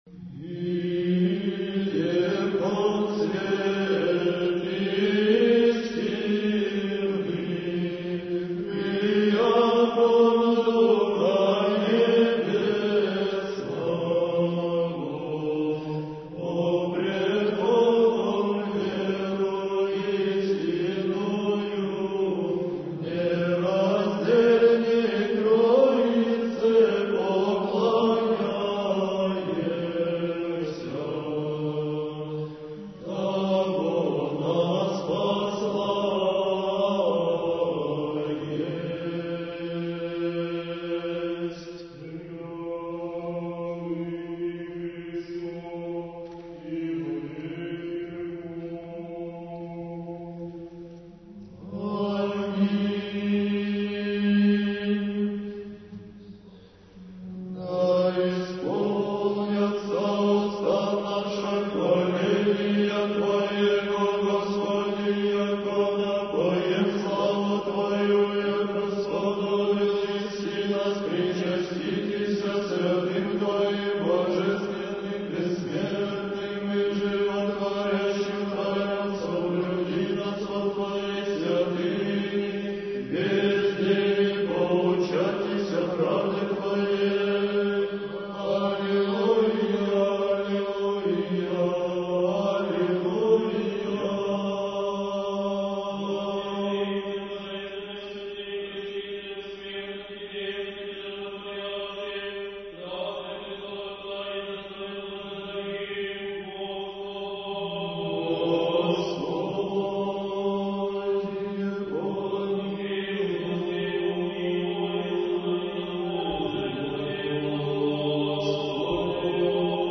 Архив mp3 / Духовная музыка / Русская / Хор Троице-Сергиевой Лавры под управлением архимандрита Матфея (Мормыля) / Литургия в Черниговско-Гефсиманском скиту /